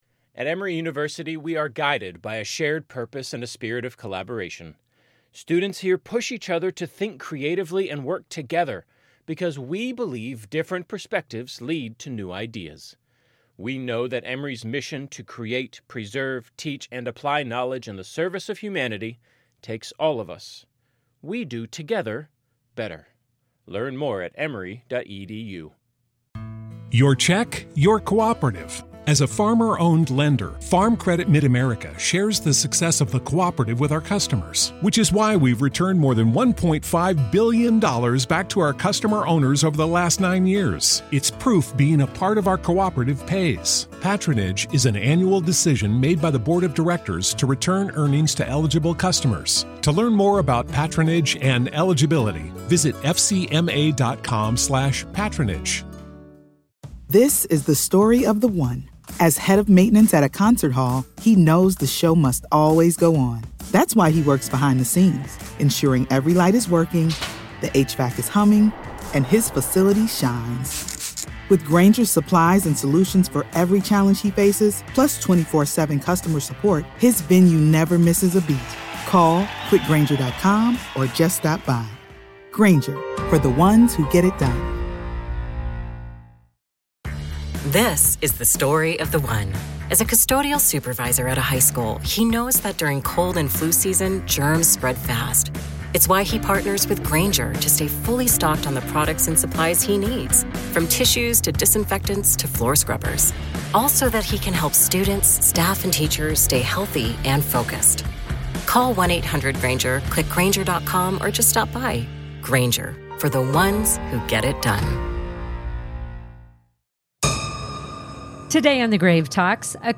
Reports of hauntings date back to the 1950s when a jailor refused to sleep in his quarters because he believed it to be haunted. In Part Two of our conversation